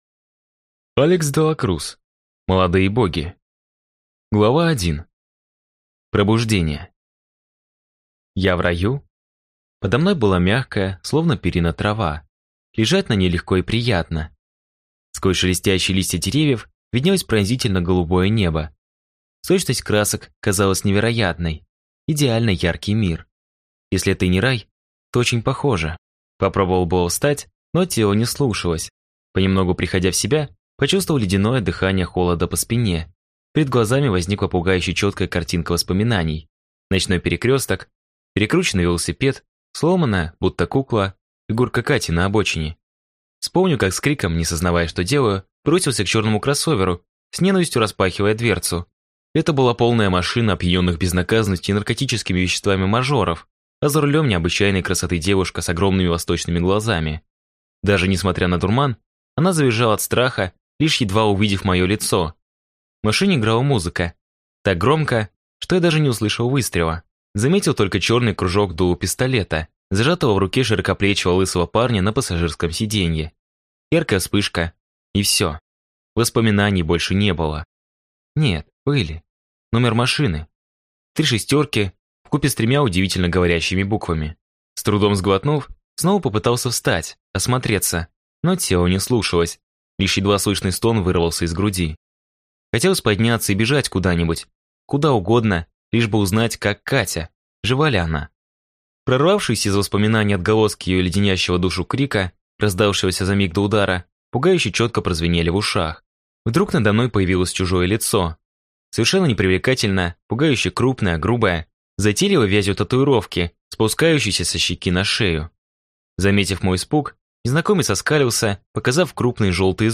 Аудиокнига Молодые боги | Библиотека аудиокниг